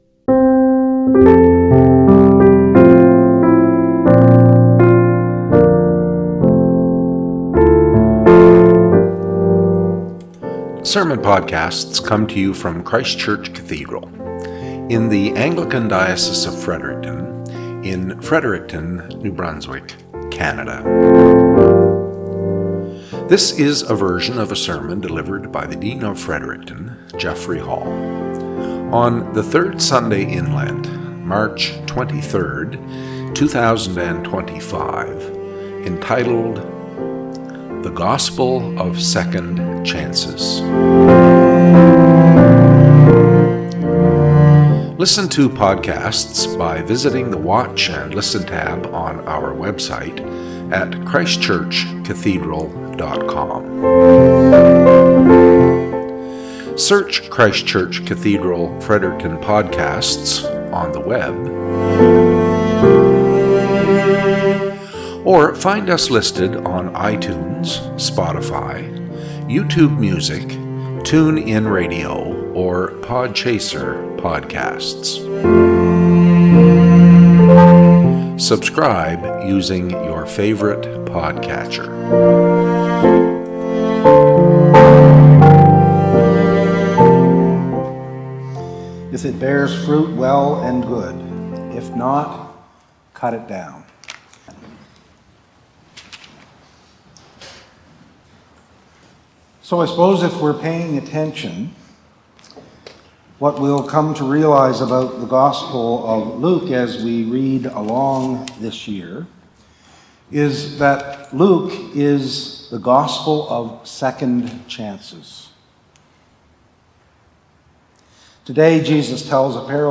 Cathedral Podcast - SERMON -
Podcast from Christ Church Cathedral Fredericton
Lections for the Third Sunday in Lent Year C